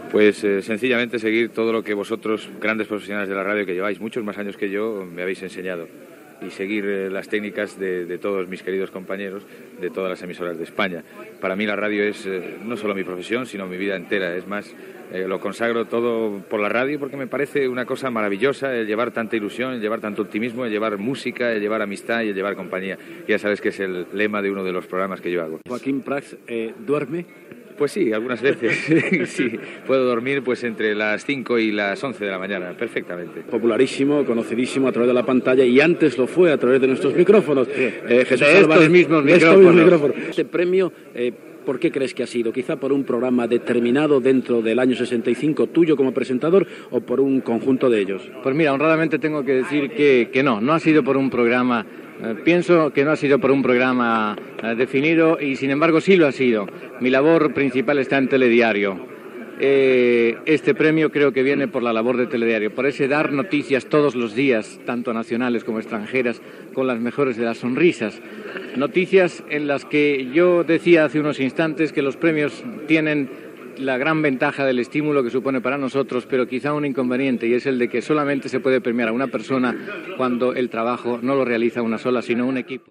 Lliurament en un hotel de Madrid de los Premios Nacionales de Radio y Televisión.
Declaracions de dos dels guardonats: Joaquín Prat, premi al millor locutor, i Jesús Álvarez García, premi de televisió.
Informatiu